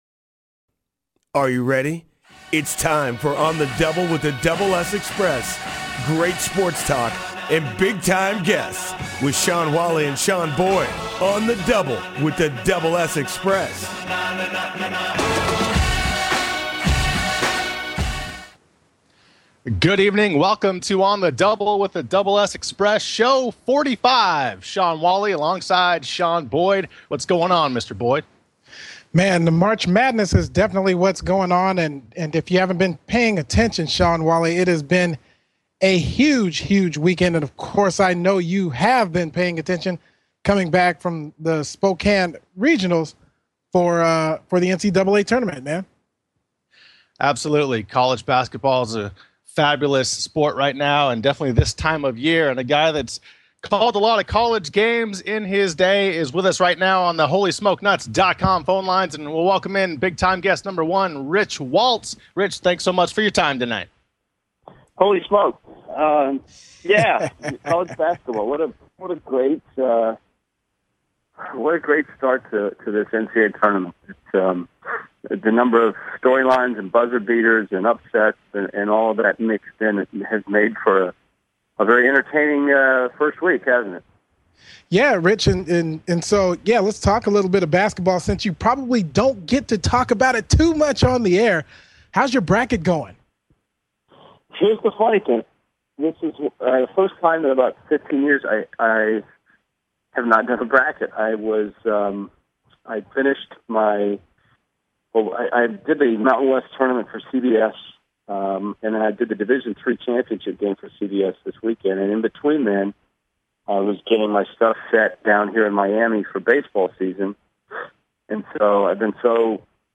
Talk Show Episode
Play-by-Play broadcaster Rich Waltz joins the show to talk about the NCAA Tournament and his illustrious career in sports broadcasting.